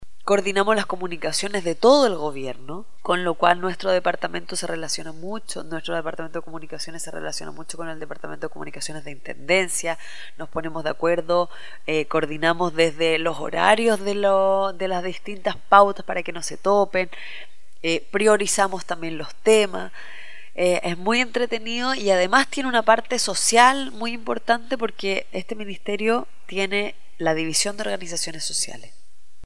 Proyecto “El mundo cívico que todos debemos conocer” entrevista a la Seremi de Gobierno Pía Bersezio